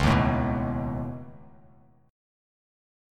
Db7sus2#5 chord